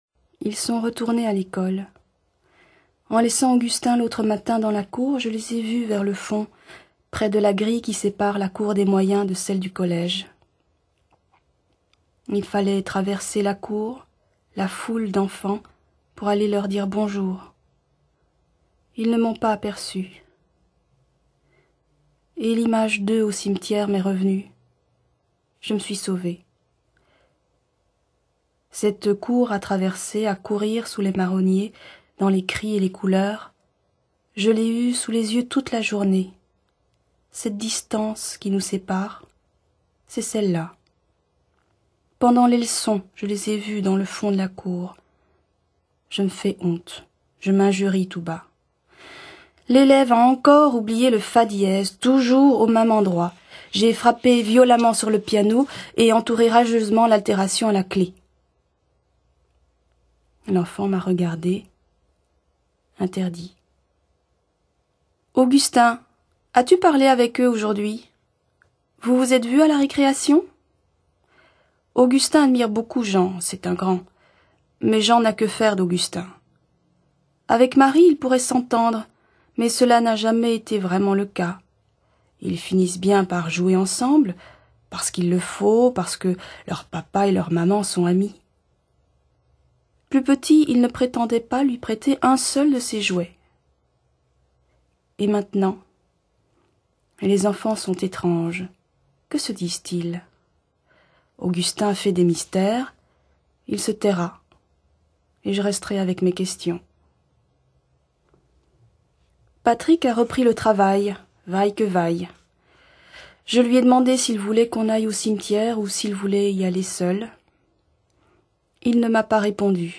Un roman à quatre voix qui reprend cette "crainte qui nous hante" tous, d'une manière sobre et entêtante... La lecture intégrale